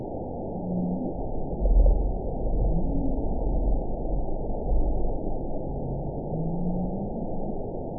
event 919590 date 01/13/24 time 13:43:48 GMT (1 year, 5 months ago) score 6.31 location TSS-AB08 detected by nrw target species NRW annotations +NRW Spectrogram: Frequency (kHz) vs. Time (s) audio not available .wav